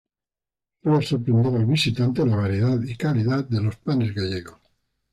Read more Article Pron Frequency A1 Pronounced as (IPA) /los/ Etymology Inherited from Latin illōs In summary Inherited from Old Spanish los (“the; them”), from Latin illōs accusative plural masculine of ille.